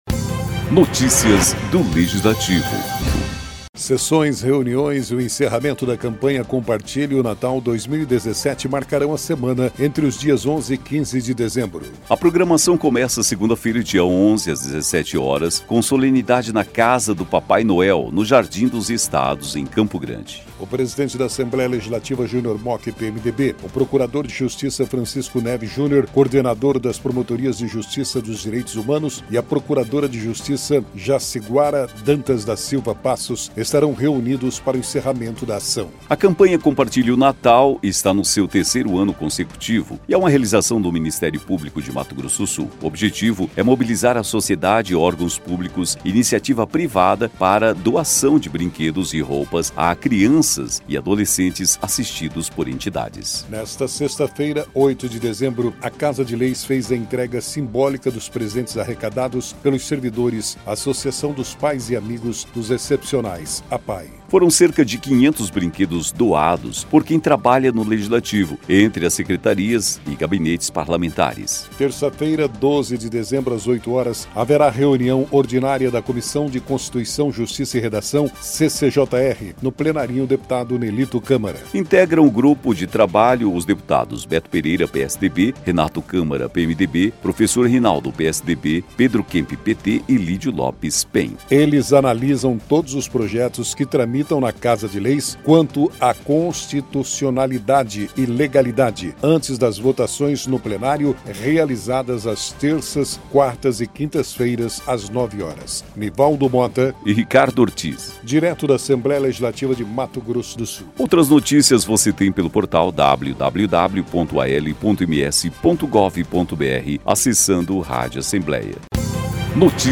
Equipe Rádio Assembleia em 11/12/2017 10:17:00